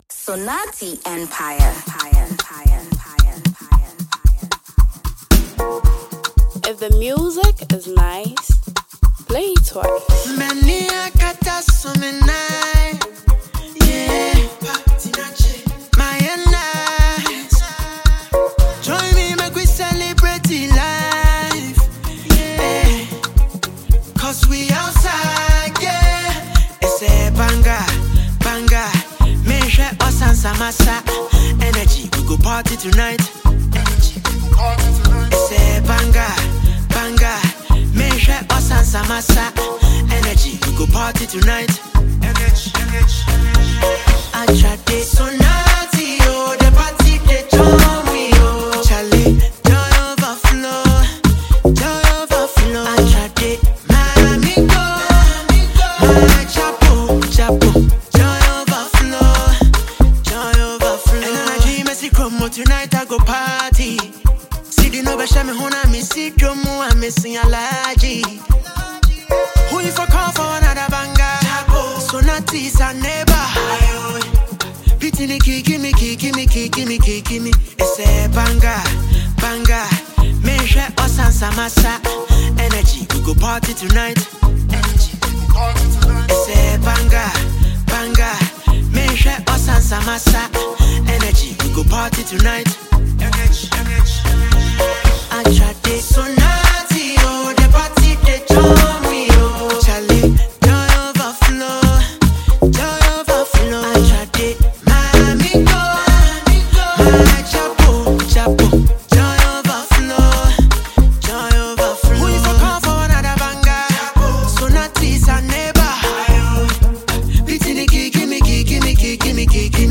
creating an energetic atmosphere perfect for every occasion.